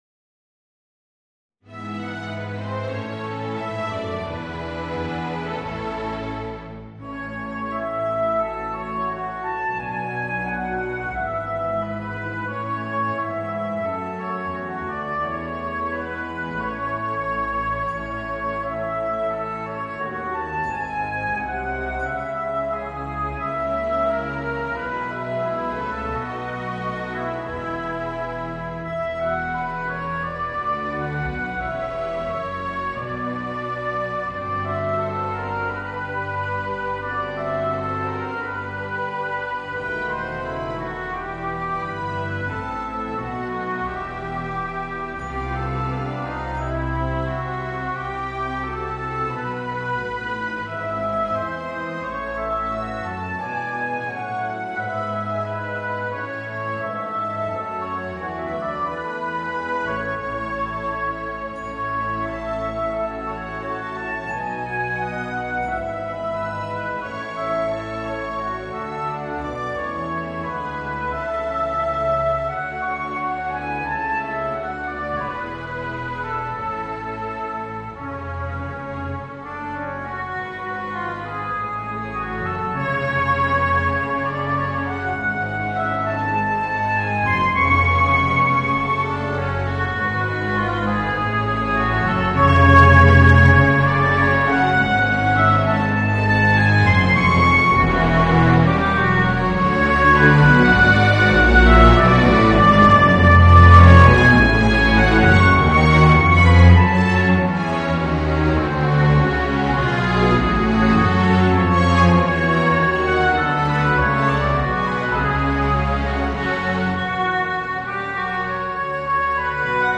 Voicing: Oboe and Orchestra